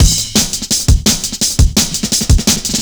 cw_170_Rolling.wav